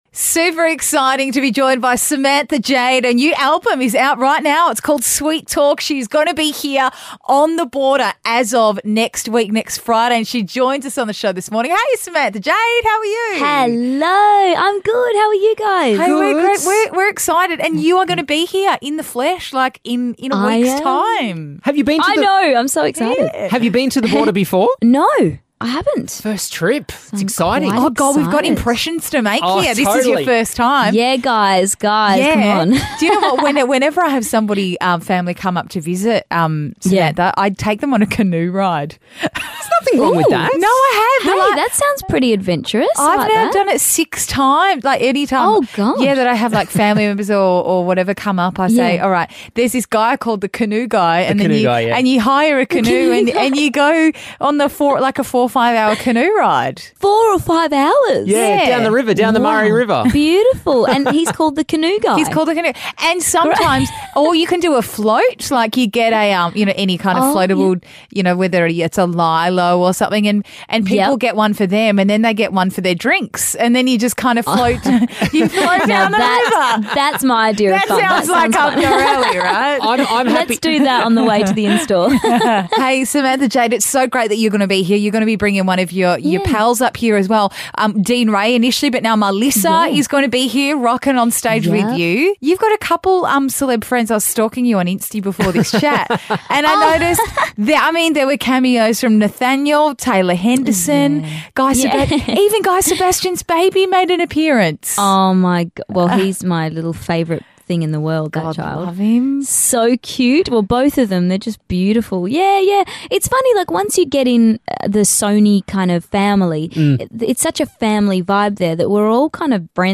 Samantha Jade dropped past the show this morning and promised to take a selfie on a selfie stick as well as go canoeing when she's here in Albury next week...YES!